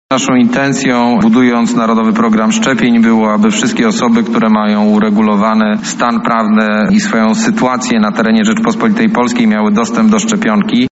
-odpowiada Dworczyk